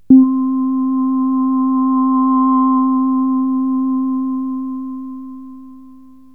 AMBIENT ATMOSPHERES-4 0010.wav